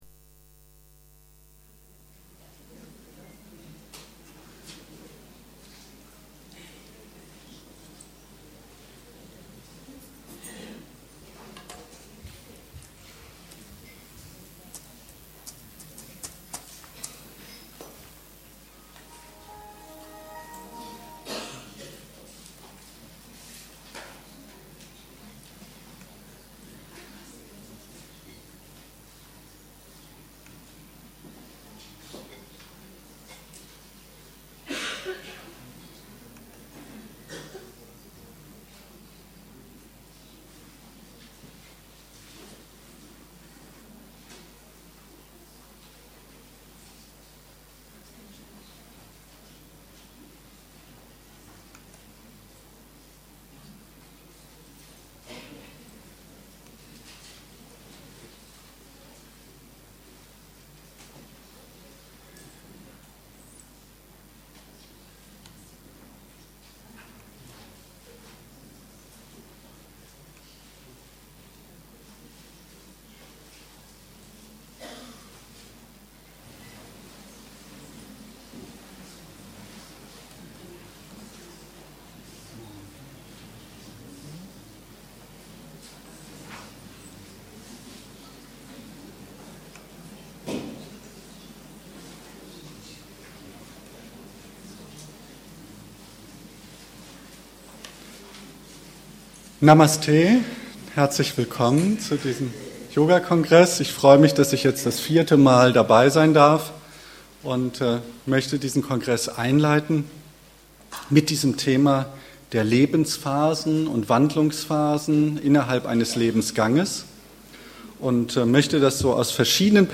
Erster Hauptvortrag des Yoga Kongresses 2008.
Es ist zwar ein Vortrag mit Beamer - er ist aber auch ohne Beamer gut zu verstehen.